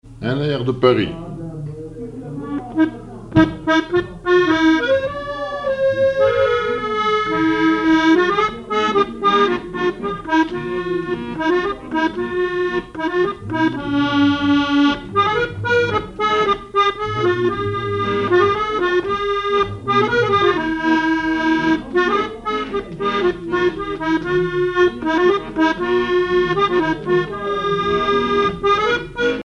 accordéon(s), accordéoniste
danse : marche
Répertoire à l'accordéon chromatique
Pièce musicale inédite